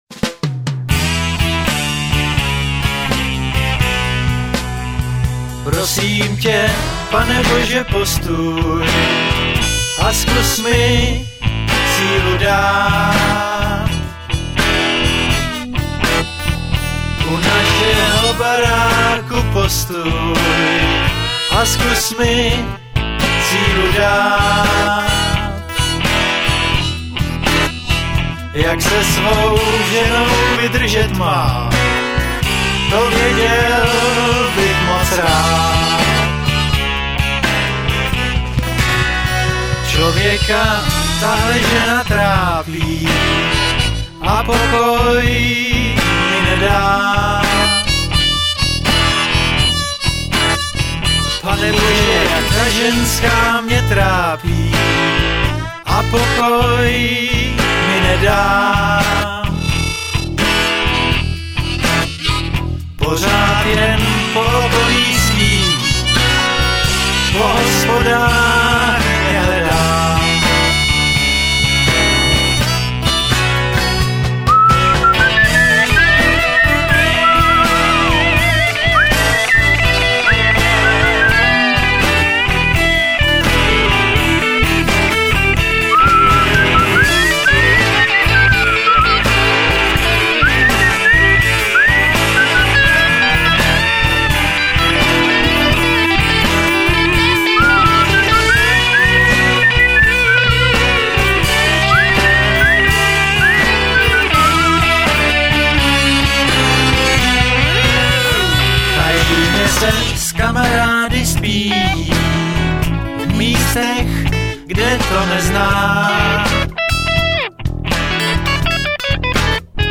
bicí a percussions
baskytara
flétna, alt-saxofon, harmonika, zpěv
kytary, zpěv